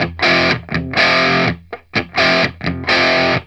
RIFF1-125F.A.wav